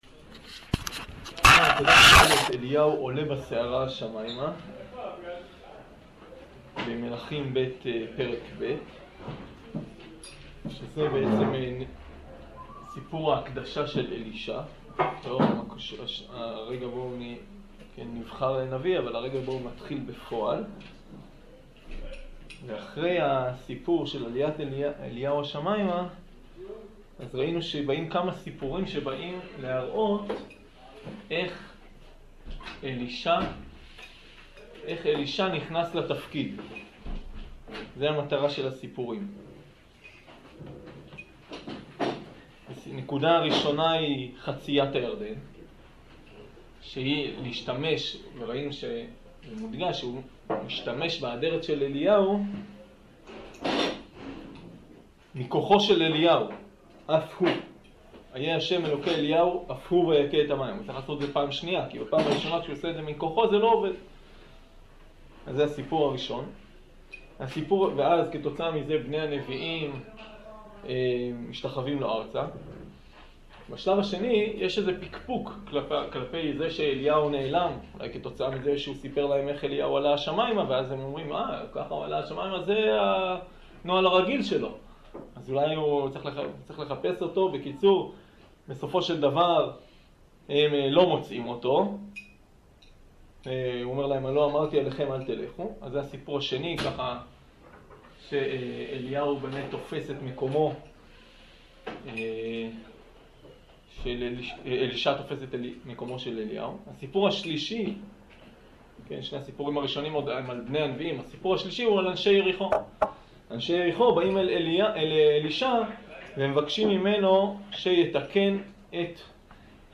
שיעור פרק ג'